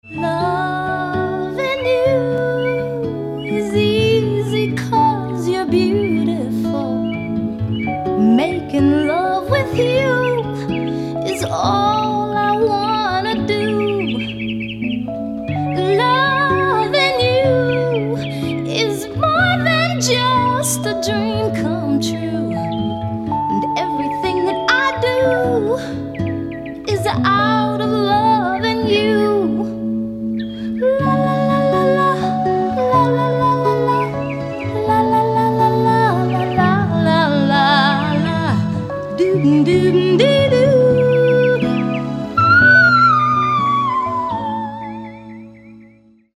• Качество: 320, Stereo
спокойные
романтичные
красивый женский голос
красивый женский вокал
soul
Пение птиц
Rhythm & Blues